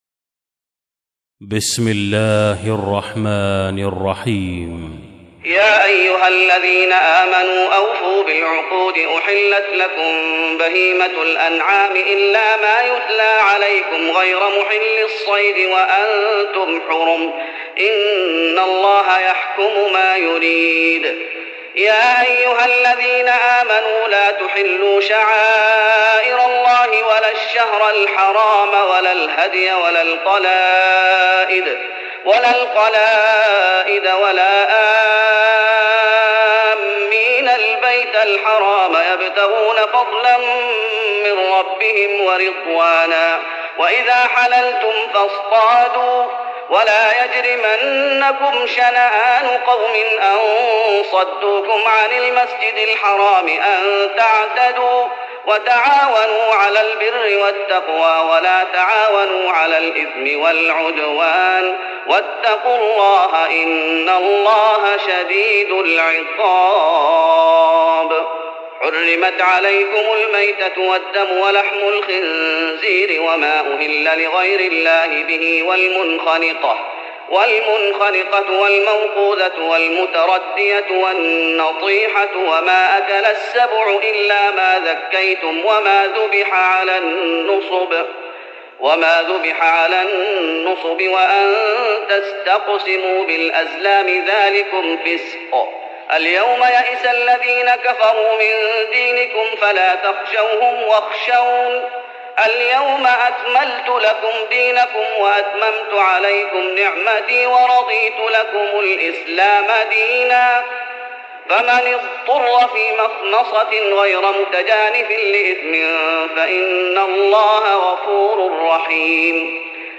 تراويح رمضان 1415هـ من سورة المائدة (1-26) Taraweeh Ramadan 1415H from Surah AlMa'idah > تراويح الشيخ محمد أيوب بالنبوي 1415 🕌 > التراويح - تلاوات الحرمين